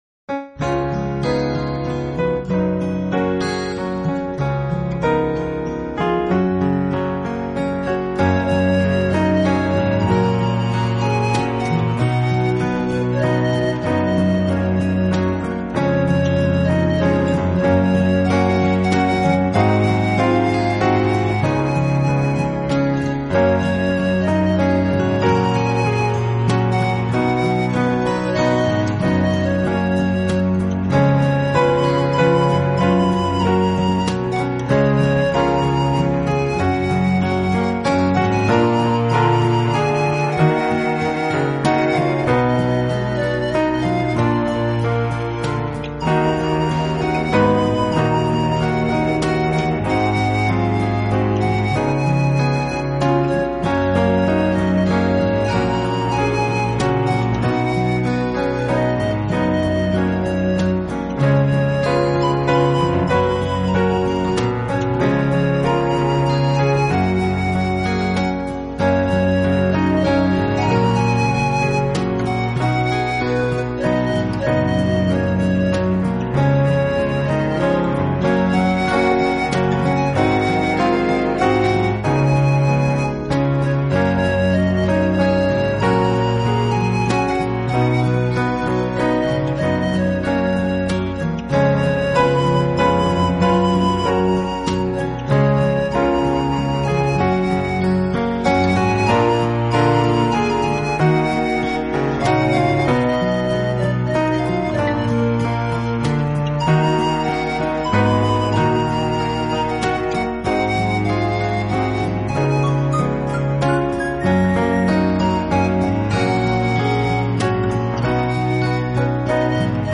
Easy Listening